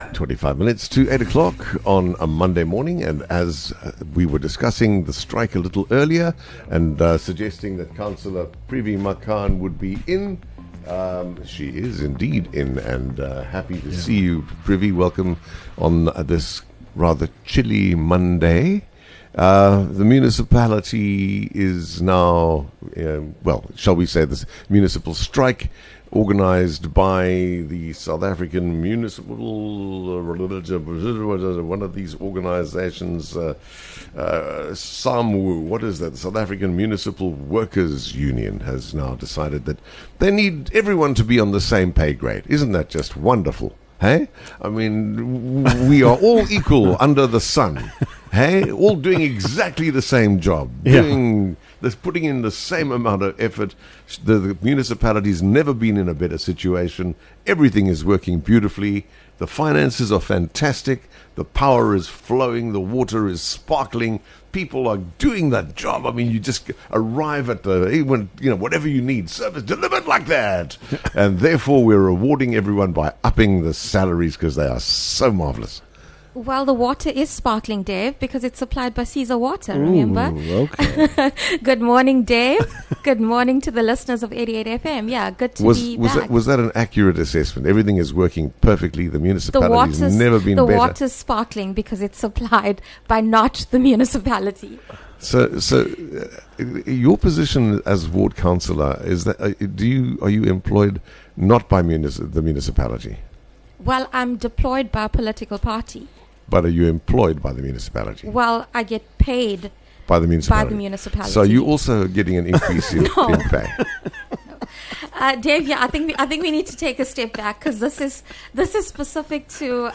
5 May Ward Counselor Privi Makhan gives an update on the Municipal Strike